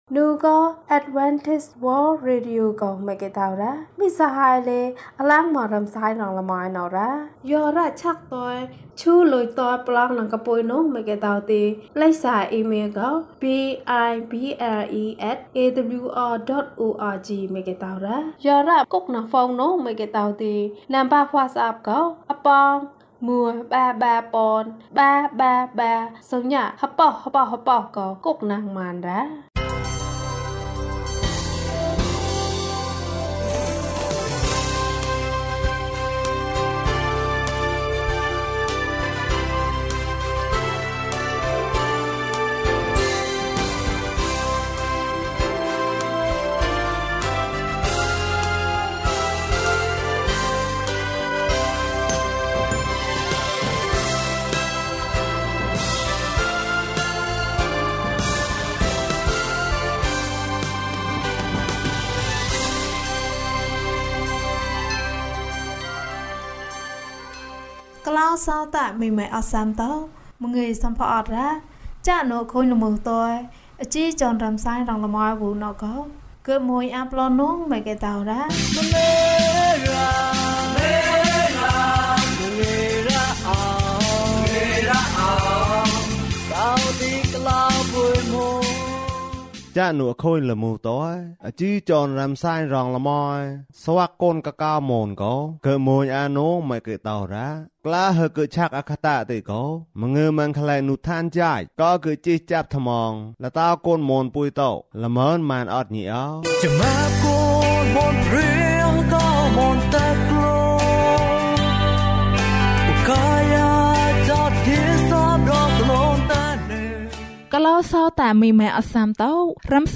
ဘုရားသခင်နှင့်အတူ ကုစားခြင်း။ ကျန်းမာခြင်းအကြောင်းအရာ။ ဓမ္မသီချင်း။ တရားဒေသနာ။